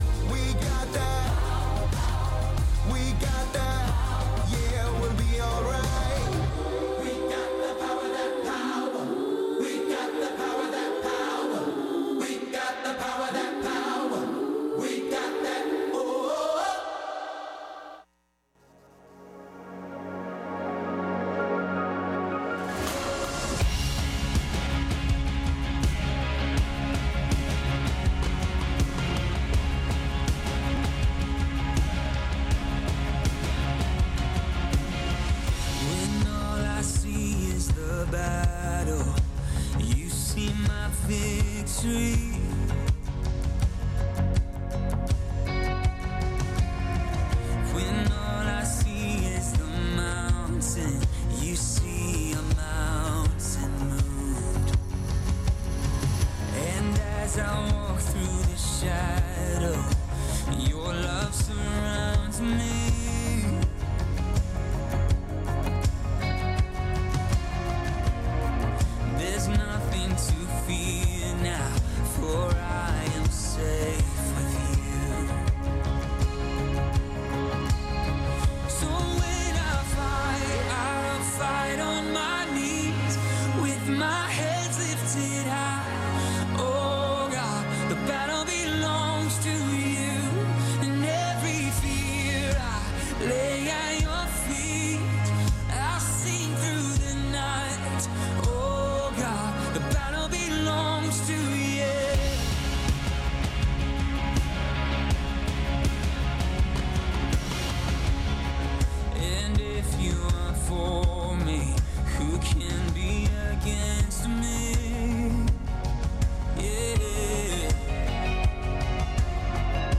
Worship and Prayer Night